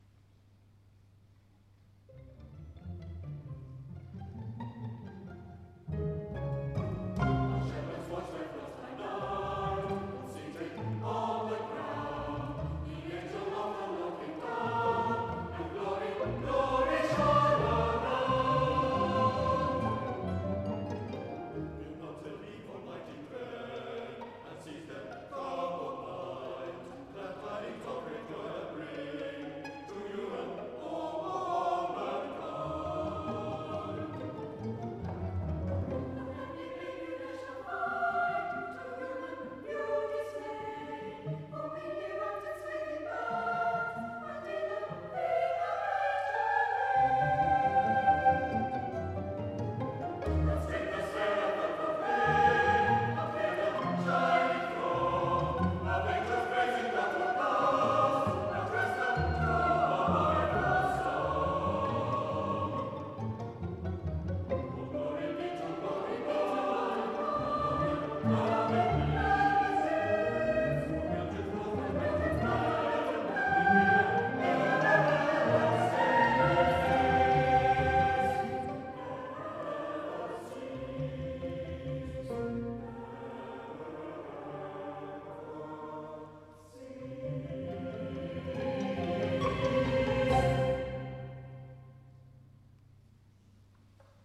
Five settings of Christmas carol words for chorus, soprano solo, strings and harp.